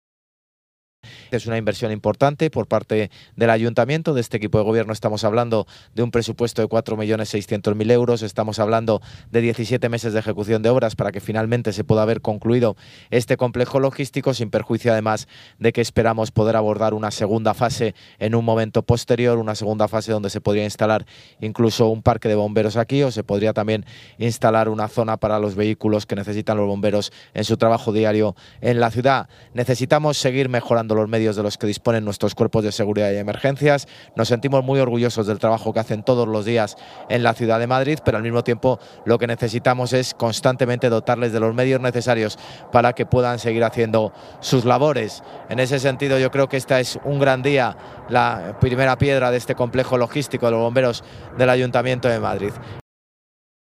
El alcalde ha colocado la primera piedra acompañado de las delegadas de Obras y Equipamientos y de Seguridad y Emergencias y la concejala del distrito
Nueva ventana:Intervención del alcalde de Madrid, José Luis Martínez-Almeida